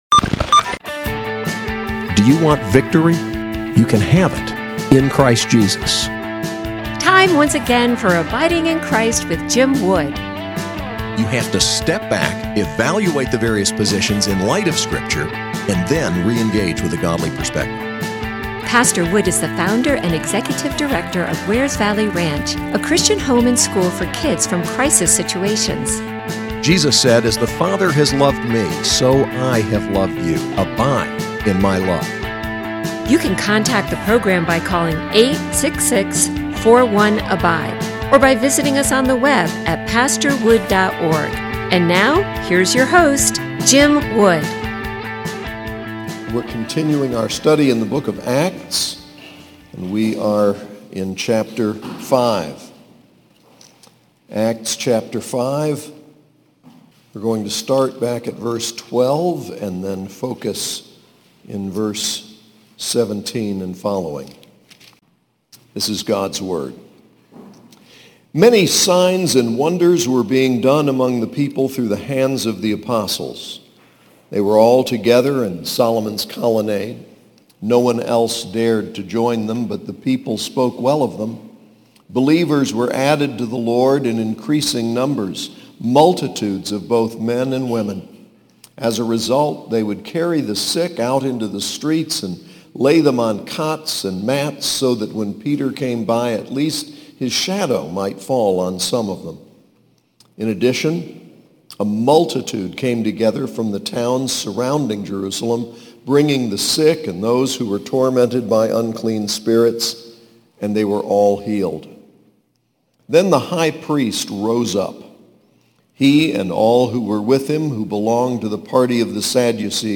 SAS Chapel: Acts 5:12-42